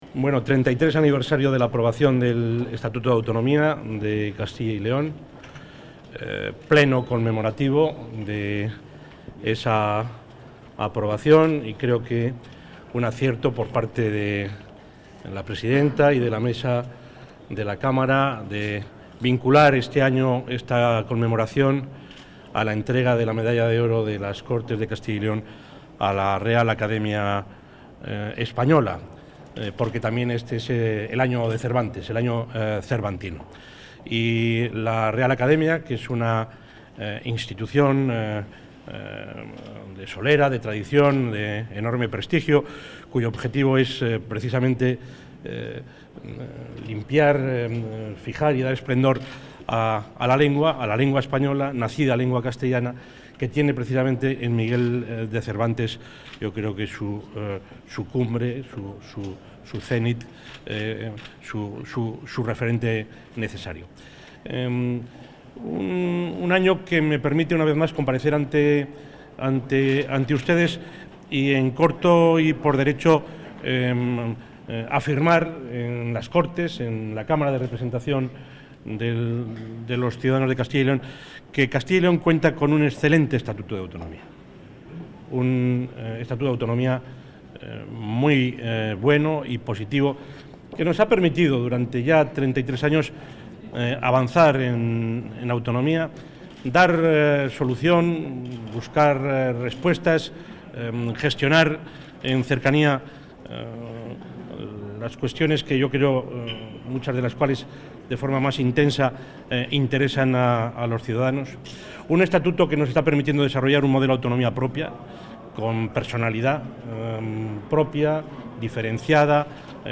Acto conmemorativo del XXXIII aniversario del Estatuto de Autonomía.